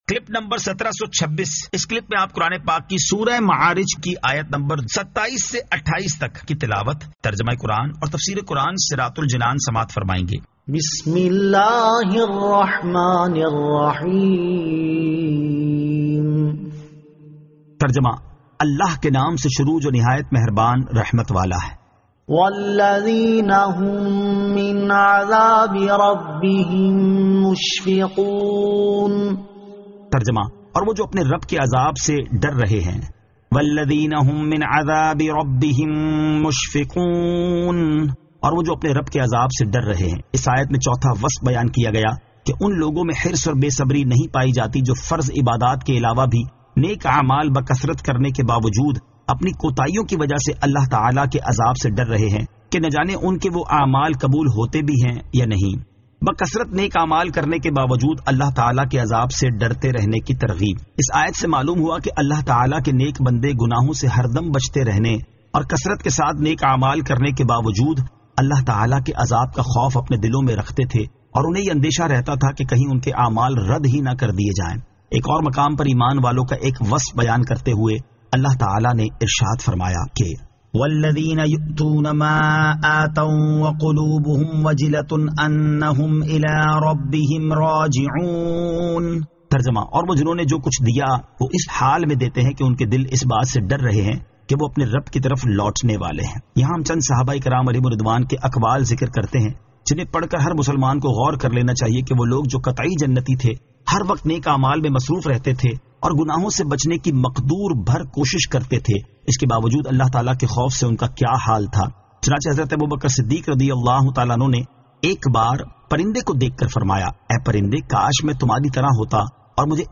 Surah Al-Ma'arij 27 To 28 Tilawat , Tarjama , Tafseer